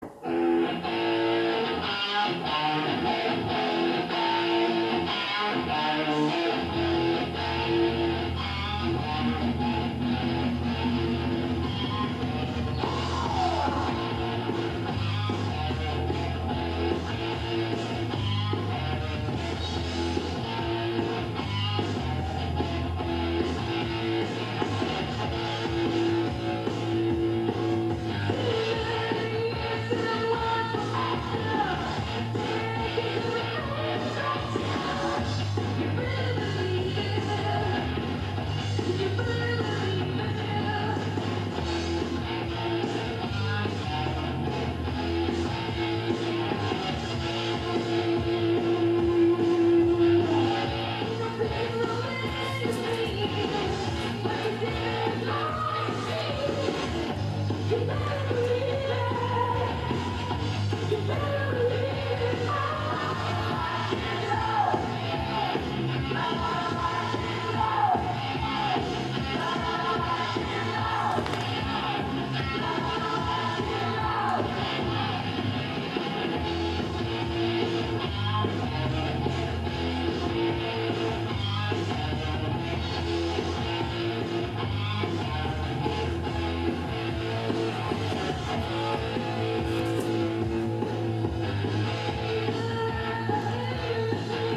Ich habe ihn mal gefragt, wie das Stück heißt, er meinte: "Dreaming", aber von wem das genau stammt, wusst er wohl auch nicht. Ich habe mal einen Teil davon eingespielt (sorry für die Patzer ).